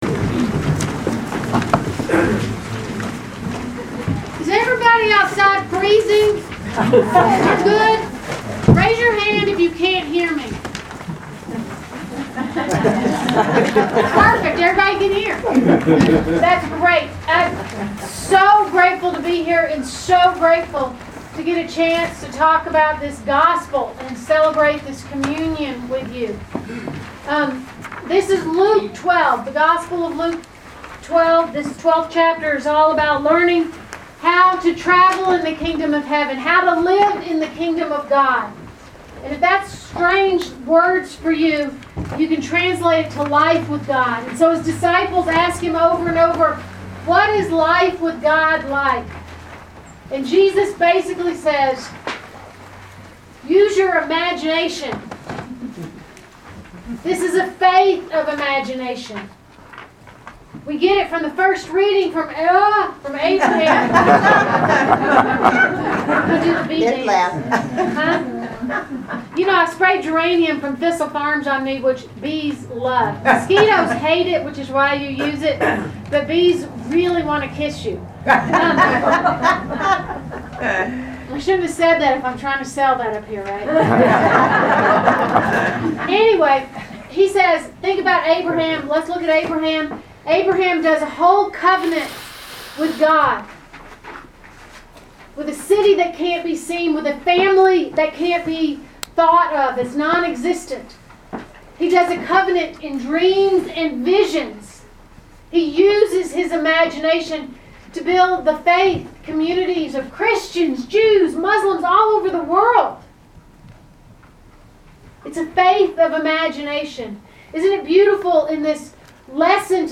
Proper 14 at Chapel of the Transfiguration
Sermons from St. John's Episcopal Church